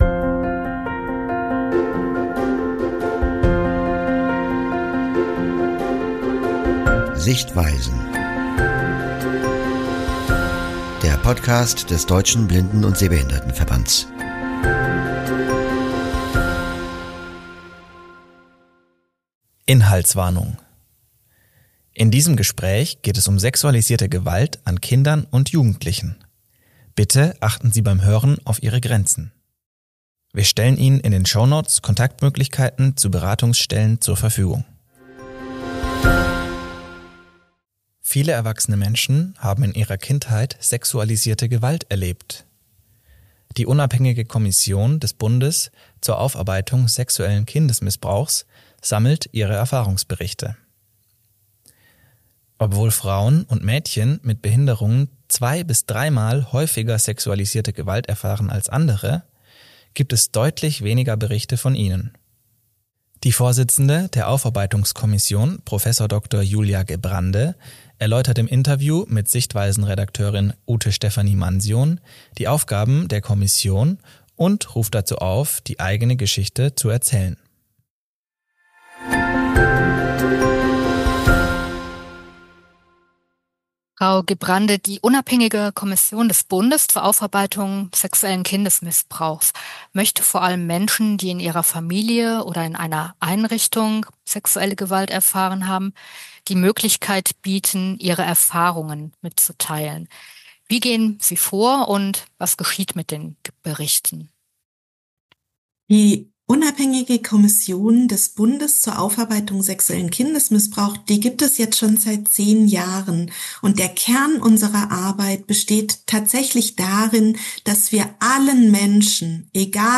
Beschreibung vor 3 Tagen Inhaltswarnung: In diesem Gespräch geht es um sexualisierte Gewalt an Kindern und Jugendlichen.